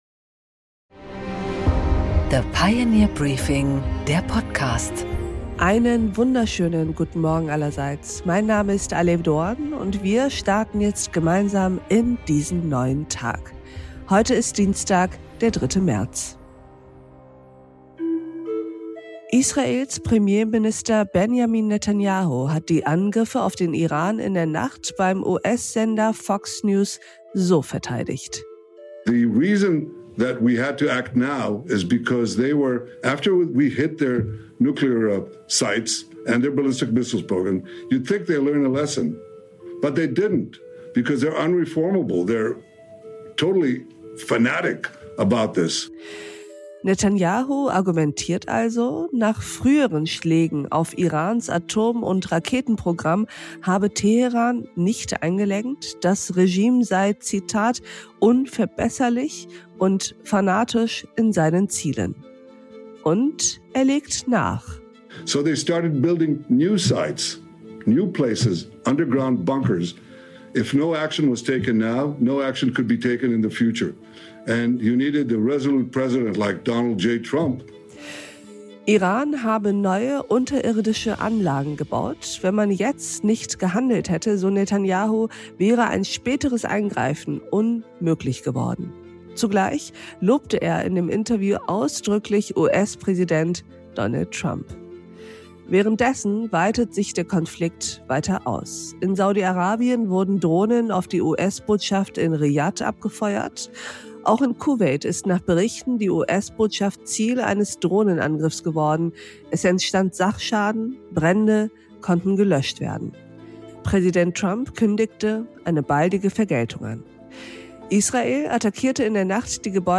Manuel Hagel im Interview | Iran am Kipppunkt | Merz im Trump-Modus ~ The Pioneer Morning Briefing - Nachrichten aus Politik und Wirtschaft Podcast